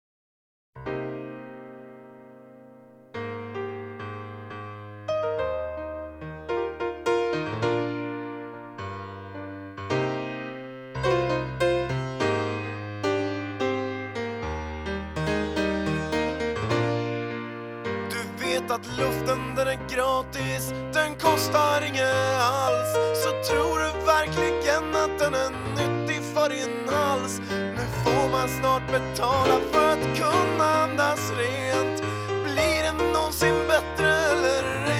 Жанр: Альтернатива / Панк